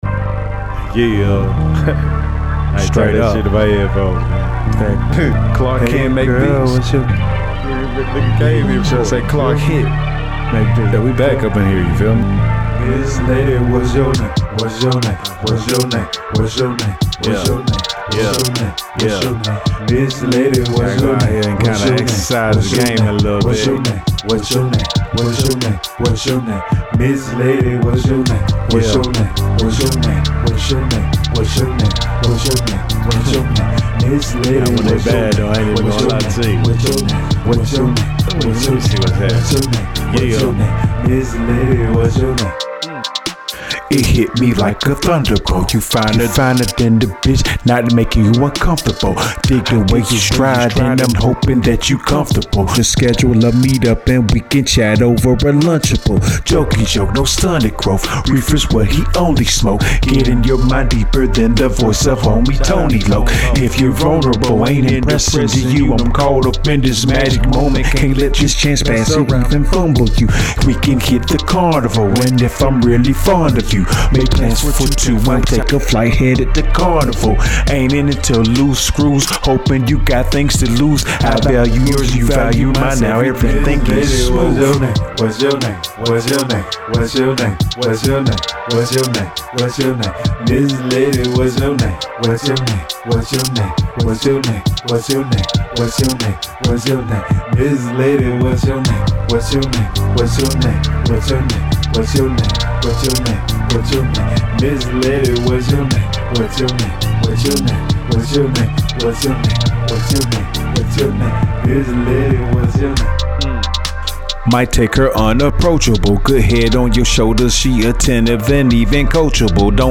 HIP HOP SOUL